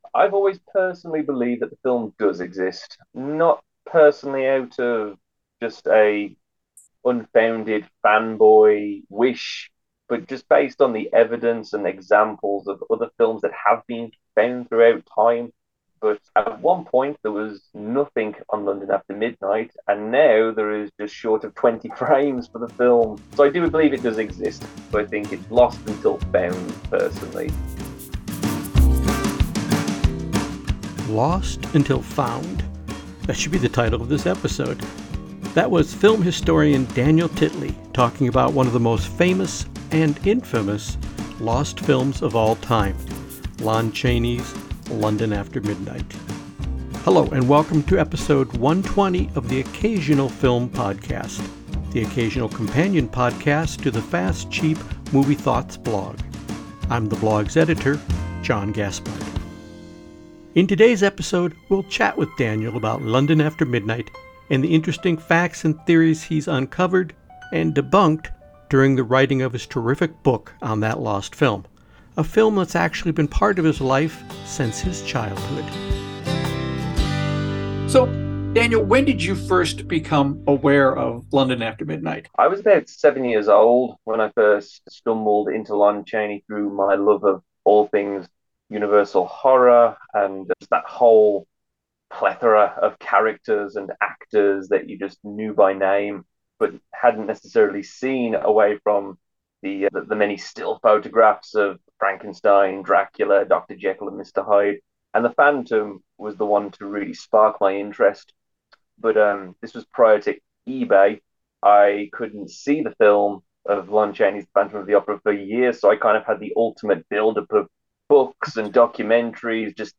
An occasional interview podcast, including conversation with filmmakers and film-lovers, discussing the movies that have made a difference in their lives.